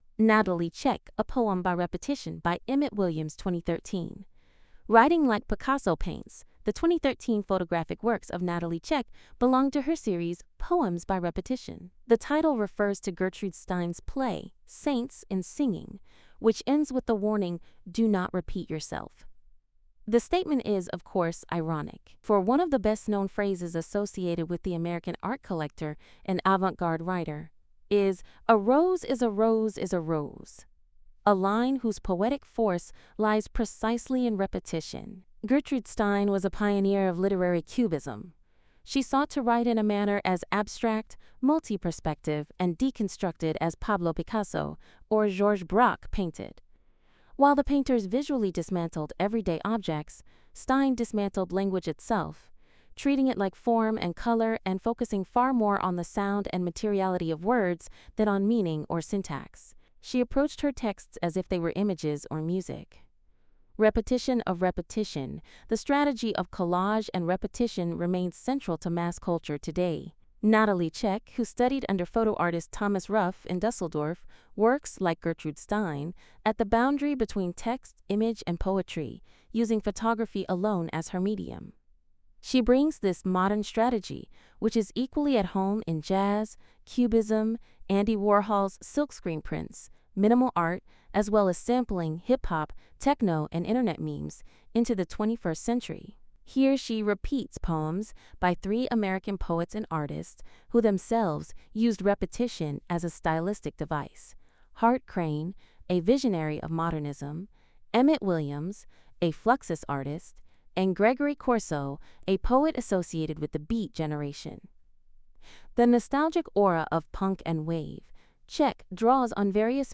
Hinweis: Die Audiotranskription ist von einer KI eingesprochen.